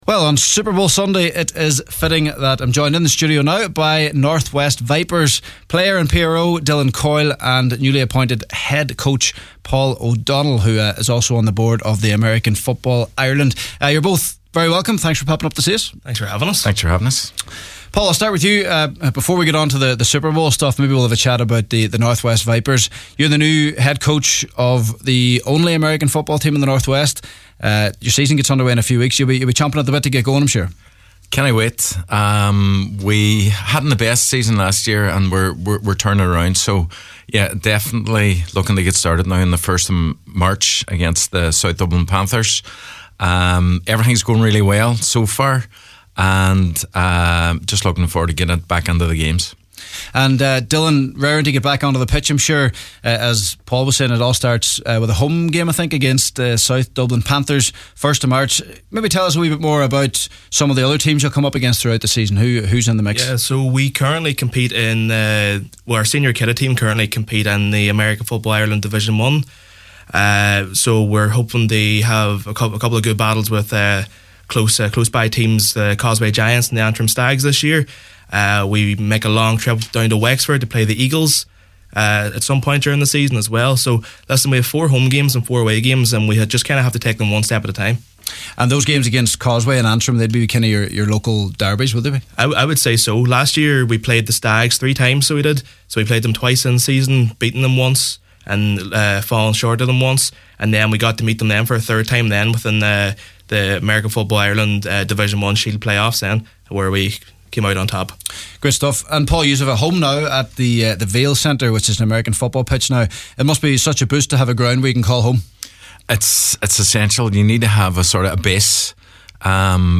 It was fitting that on Superbowl Sunday we were joined by members of the North-West Vipers American Football Team in studio this evening.